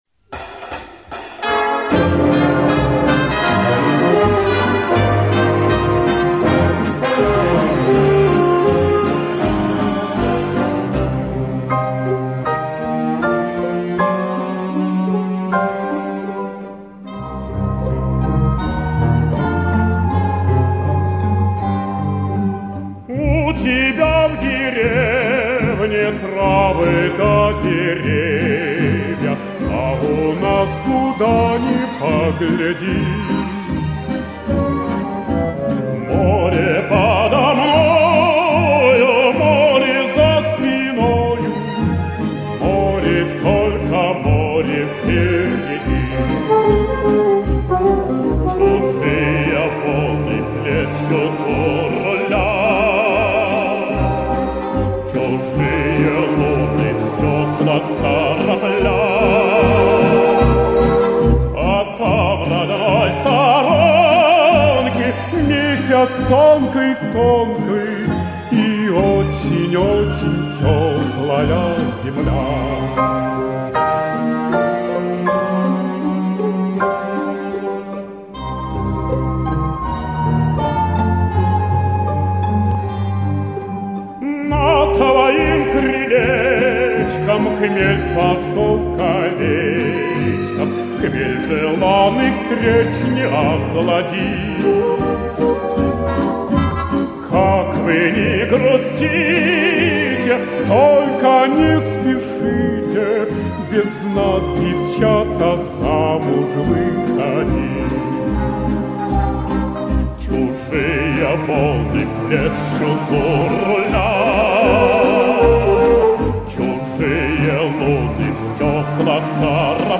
Недавно в клубе Эльдар состоялся концерт музыки Петра Тодоровского.
Первые 3 записи - с этого концерта.